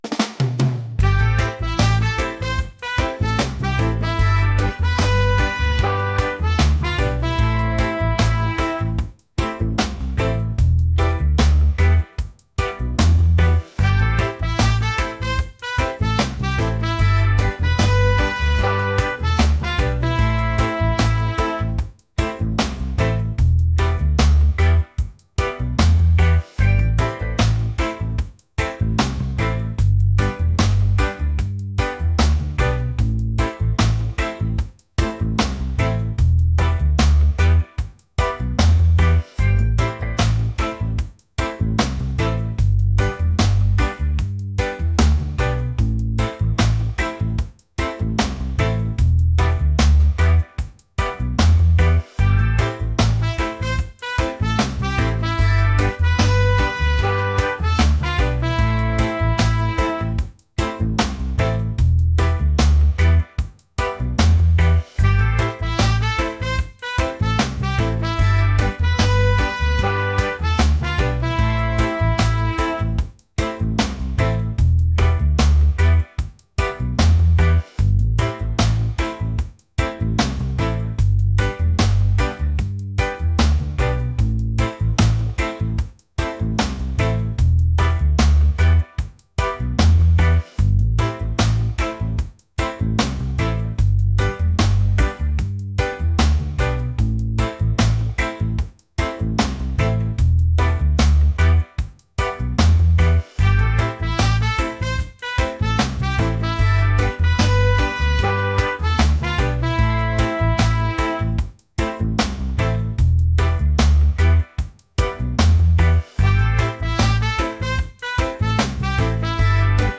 upbeat | reggae | laid-back